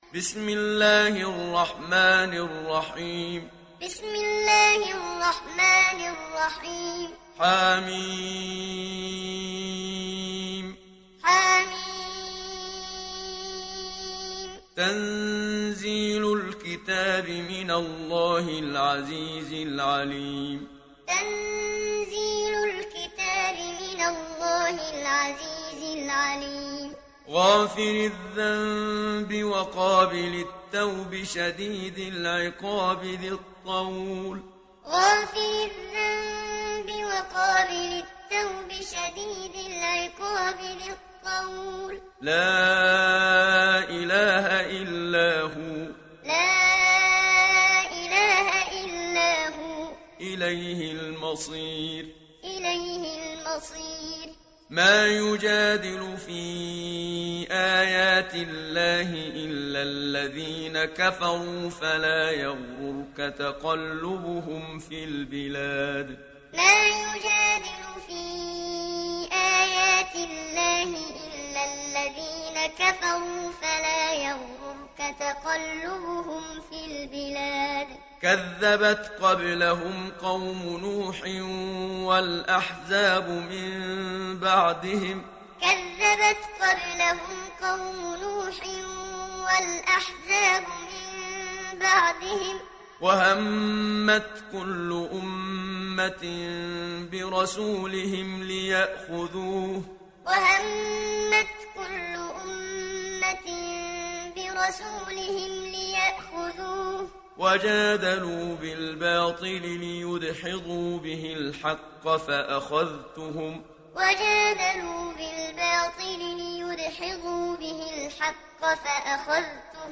İndir Mümin Suresi Muhammad Siddiq Minshawi Muallim
Muallim